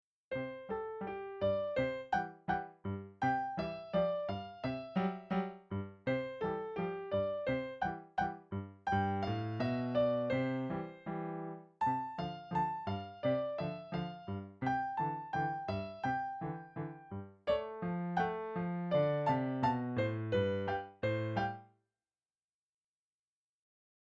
Voicing: 1 Piano 4 Hands